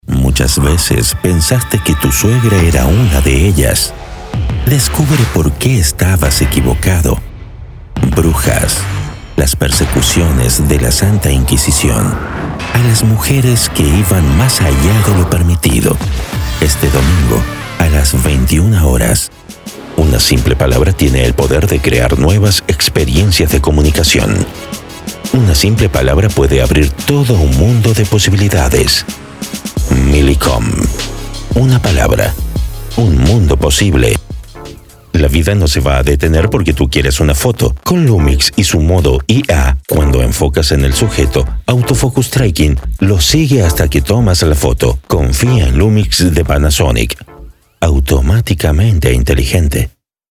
Talento con voz profunda, grave e institucional
As voice talent, I specialize in low, institutional, formal, intimate but also dynamic, energetic and promotional tones.